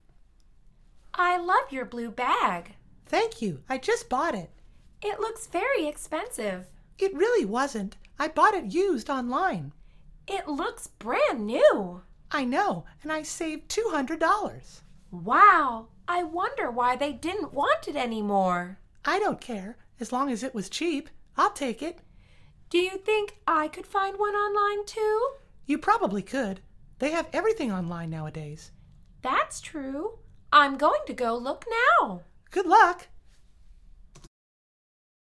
در واقع، این مکالمه مربوط به درس شماره هفتم از فصل خرید از این مجموعه می باشد.